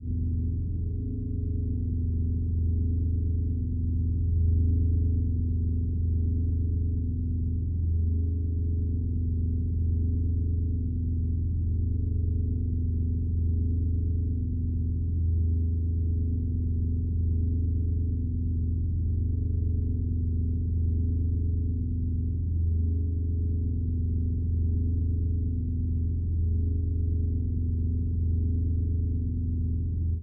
The following audio examples consist of processed, transposed and superimposed tubax sounds amplifying tonal interferences.
Audio Example: Drone in E
03_Drone_E.mp3